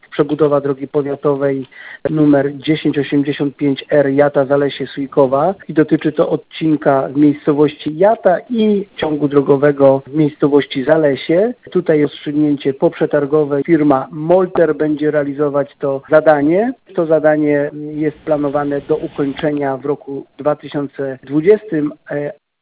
Mówi starosta niżański Robert Bednarz